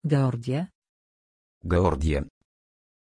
Pronunciación de Geordie
pronunciation-geordie-pl.mp3